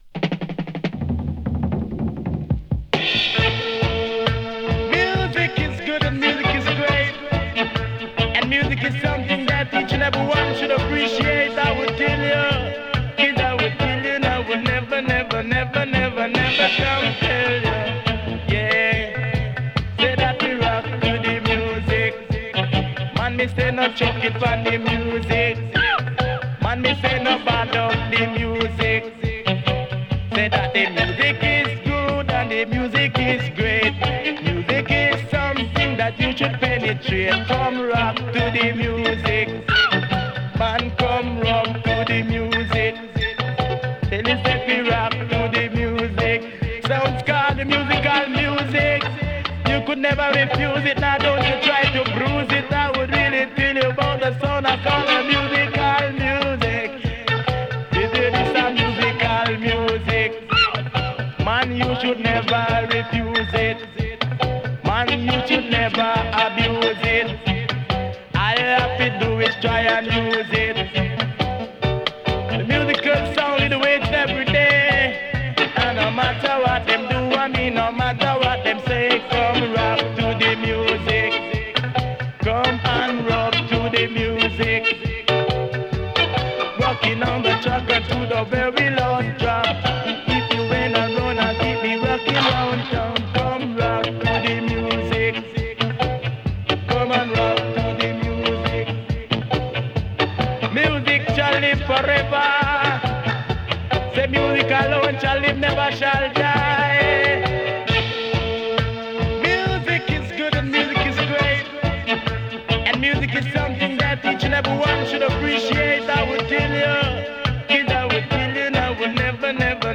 プレ ダンスホール トースティング入り ステッパーズ レゲエ
ダンスホールの原型とも言えるトースティングを聴かせるステッパーズ・レゲエ！